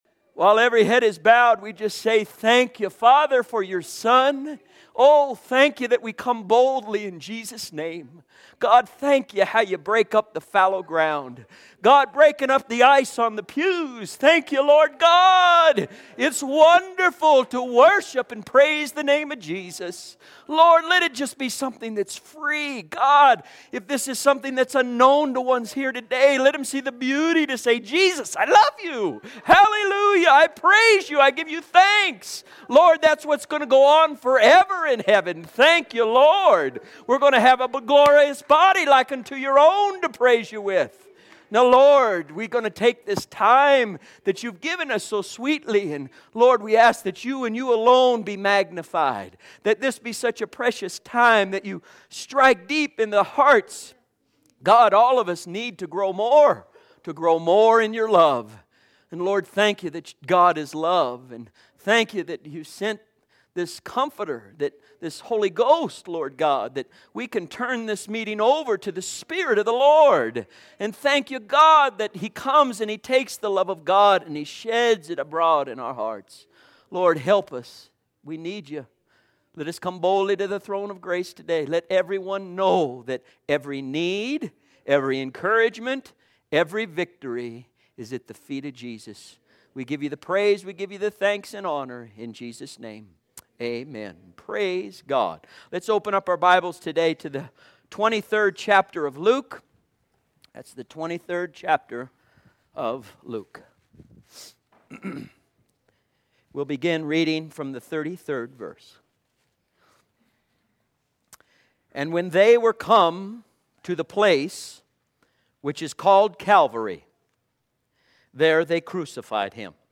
Sunday's Sermons for 2011